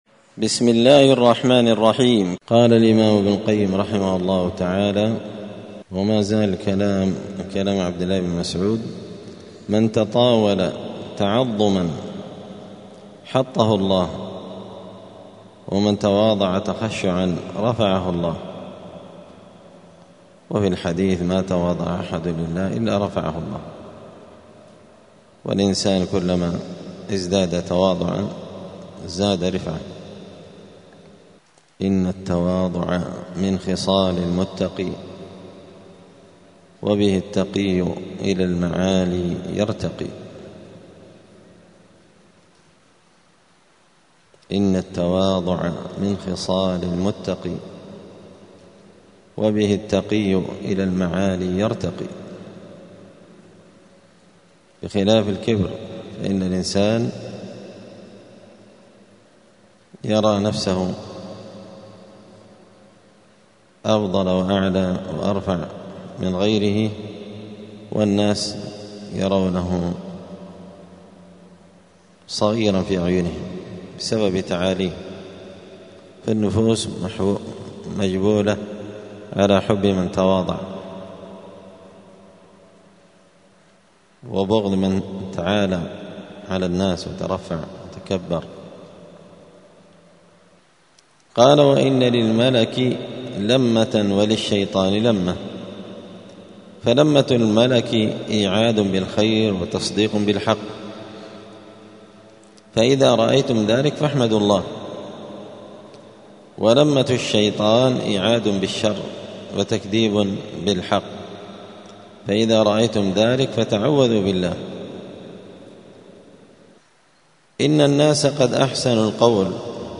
السبت 24 محرم 1447 هــــ | الدروس، دروس الآداب، كتاب الفوائد للإمام ابن القيم رحمه الله | شارك بتعليقك | 10 المشاهدات
دار الحديث السلفية بمسجد الفرقان قشن المهرة اليمن